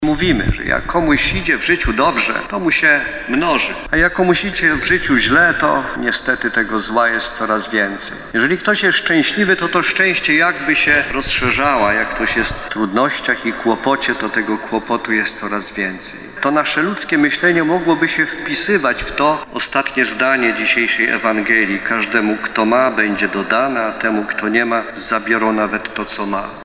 Teraz wierni w archikatedrze świętego Jana co miesiąc modlą się o szybką beatyfikację kardynała Augusta Hlonda.